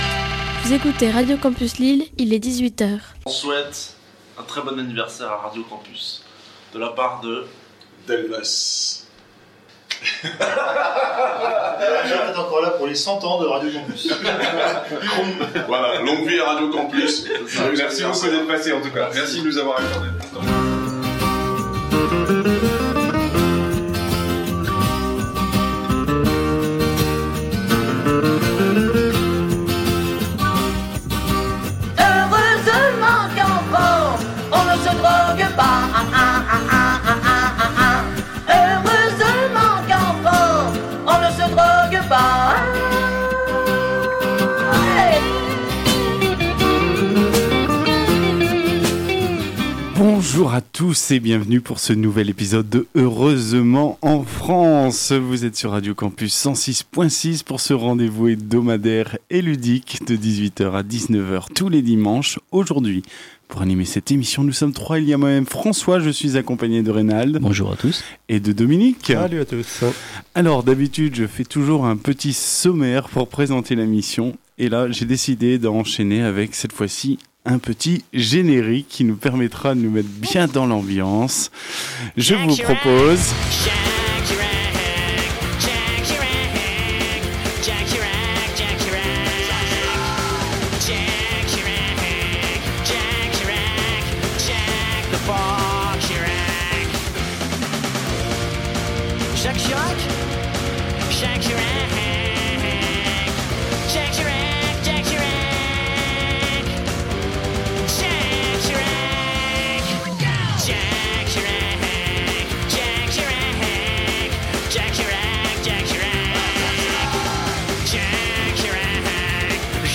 Au sommaire de cet épisode diffusé le 29 septembre 2019 sur Radio Campus 106.6 :
– Une programmation musicale très présidentielle – Retour sur les informations geek des dernières émissions